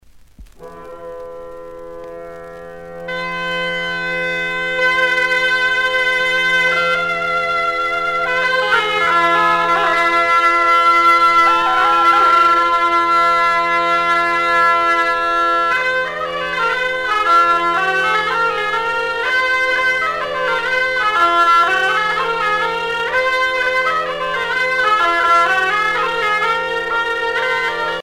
danse : fisel (bretagne)